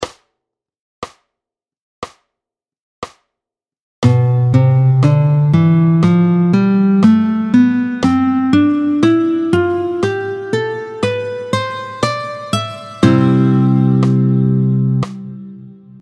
26-13 Doigté 7, Do majeur, tempo 60